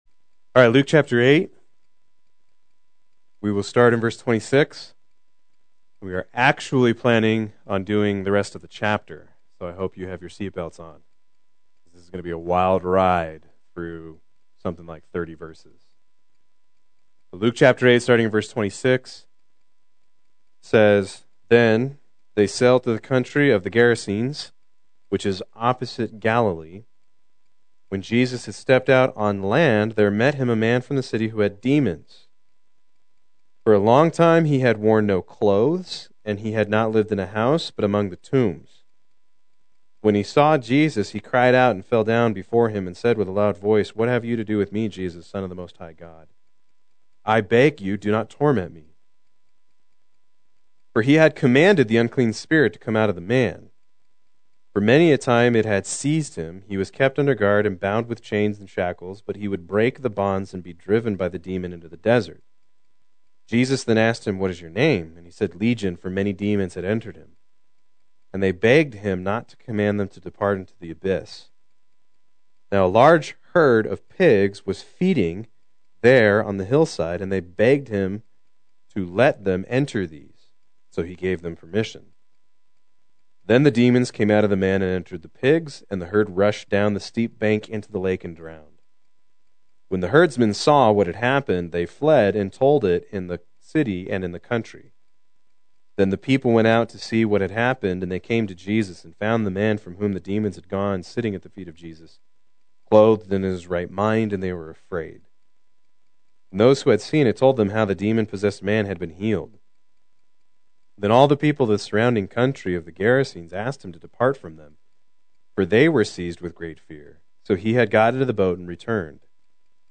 Proclaim Youth Ministry - 12/14/18
Play Sermon Get HCF Teaching Automatically.